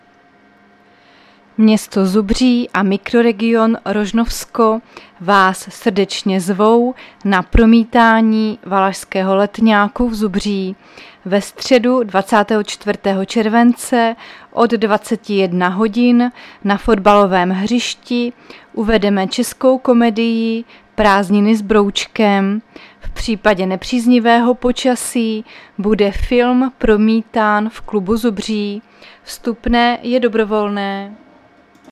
Záznam hlášení místního rozhlasu 23.7.2024